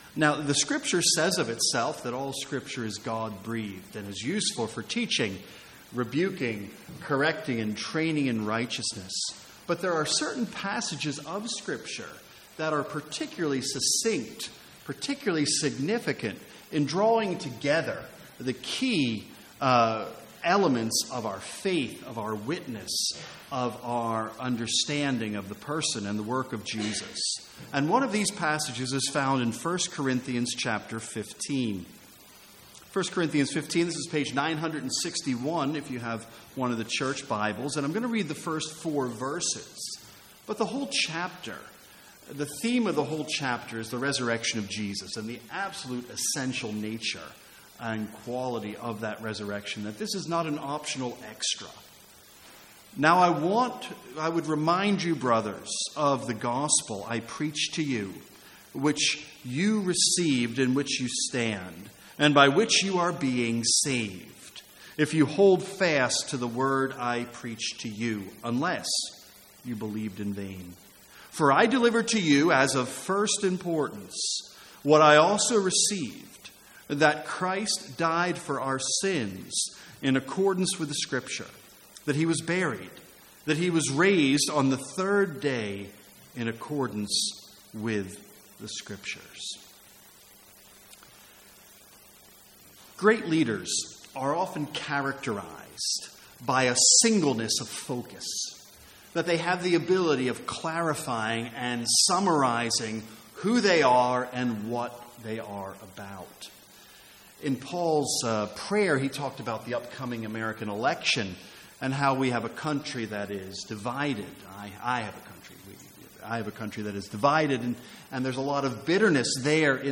A one-off Sunday evening sermon in 1 Corinthians.